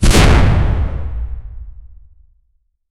Grenade Launcher Heavy, Plunging "Thwump" reminiscent of a small cannon fire. A slow "Whirring" or "Swoosh" during the arc. Impact is a Full, Heavy "Boom" (Explosion).
grenade-launcherheavy-plu-k57vhcst.wav